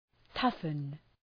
Προφορά
{‘tʌfən}